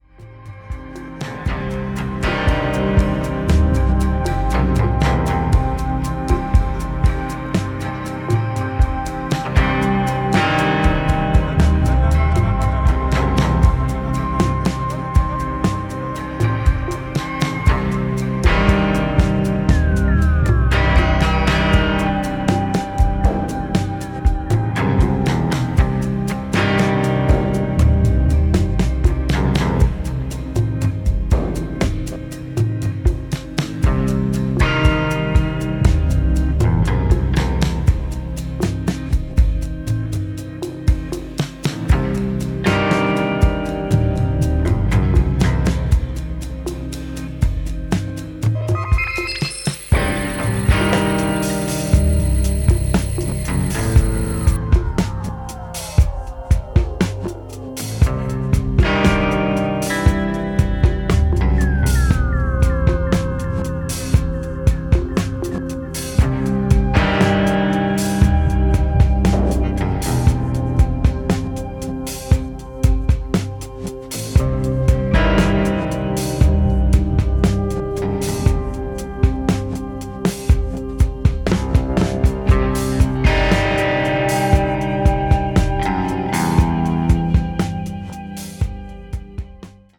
A new quartet
guitar, vocals
modular synths, keys
mc 202, syncussions, effect pedals
drums, electronics, Moog